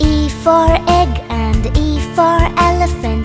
Phonics